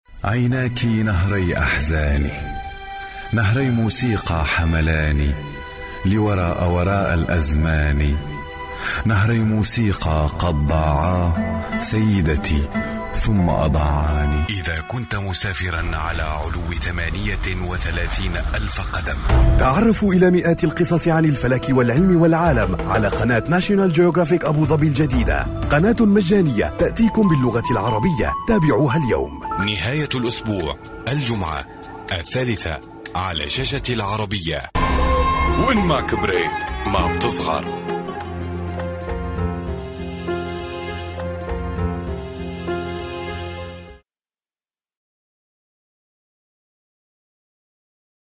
Masculino